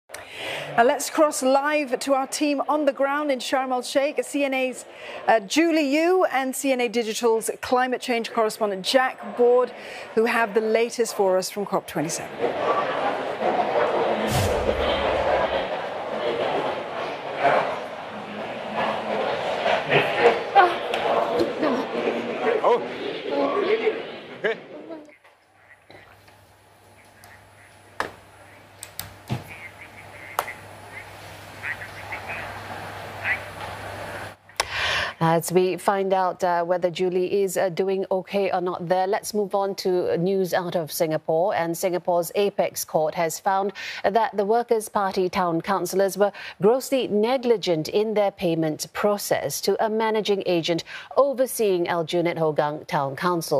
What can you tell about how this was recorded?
Faints during Live Broadcast